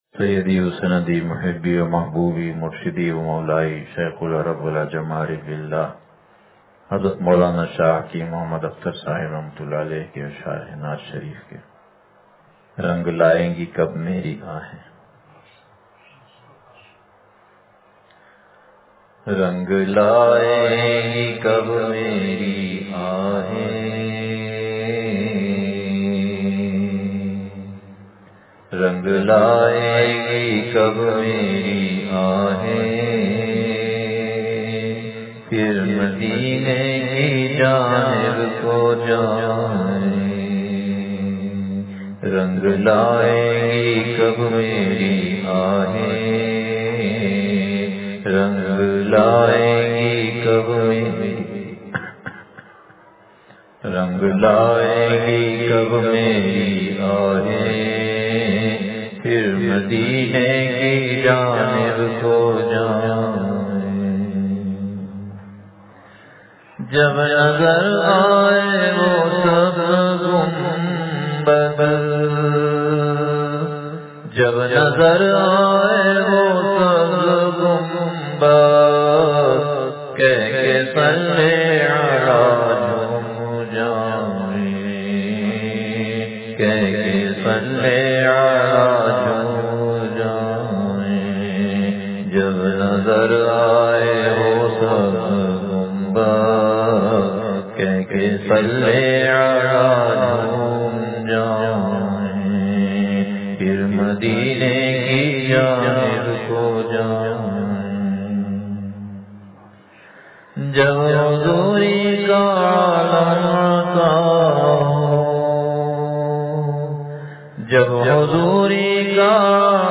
رنگ لائیں گی کب میری آہیں – مجلس بروز جمعرات